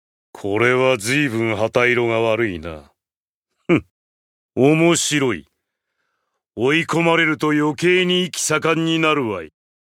「エディットモード」で男性用追加音声が使用可能になります。